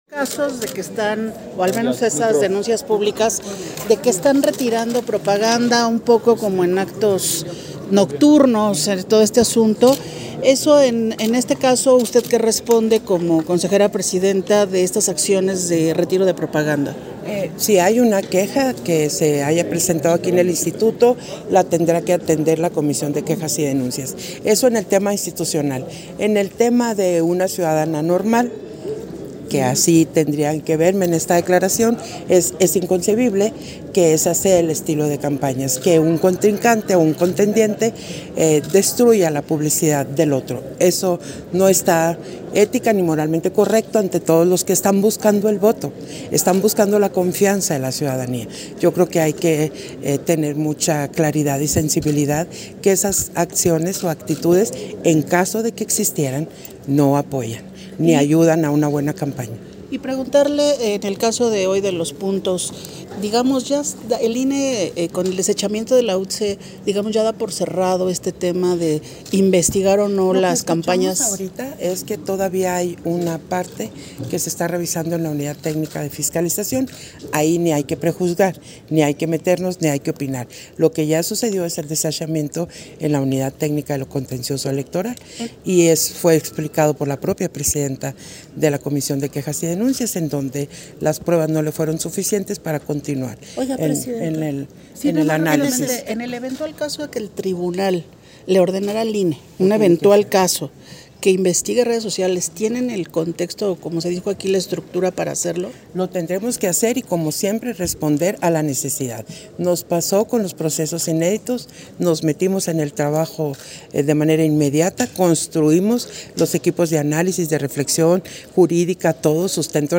Entrevista de Guadalupe Taddei, al terminó de la Sesión Extraordinaria del Consejo General
Entrevista que concedió Guadalupe Taddei, a diversos medios de comunicación, al terminó de la sesión extraordinaria del consejo general